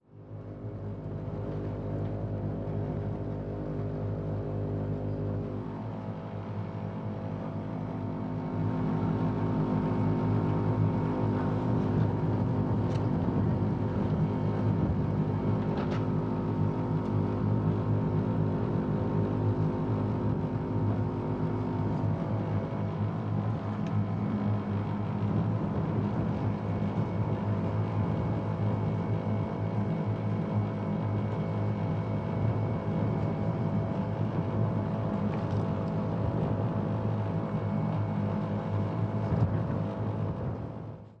Глухой гул огромного воздушного дирижабля